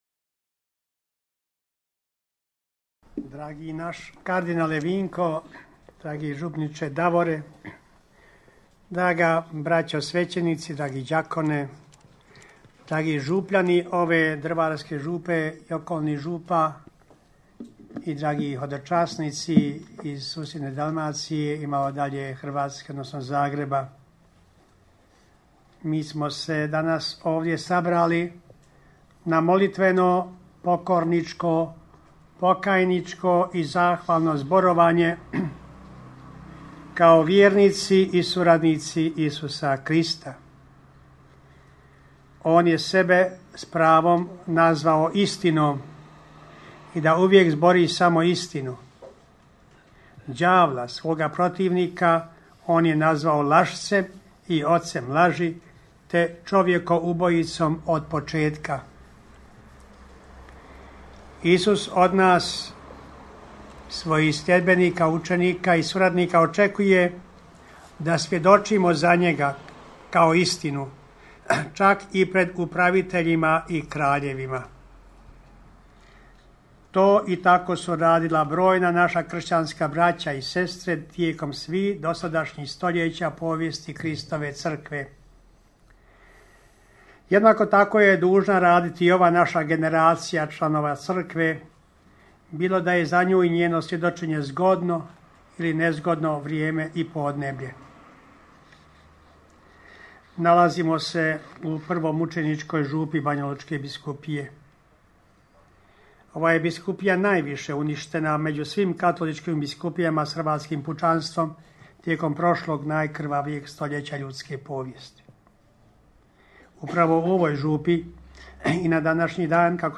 AUDIO: POZDRAV BISKUPA KOMARICE NA 80. OBLJETNICU UBOJSTVA DRVARSKOG ŽUPNIKA NESTORA I HODOČASNIKA - BANJOLUČKA BISKUPIJA
biskup banjolučki